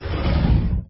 045-Push01.mp3